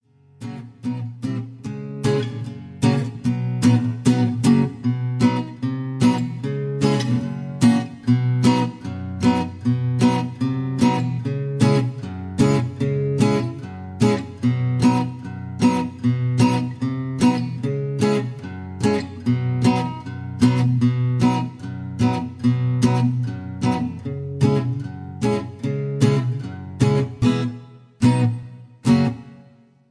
(Key-B)
Tags: backing tracks , irish songs , karaoke , sound tracks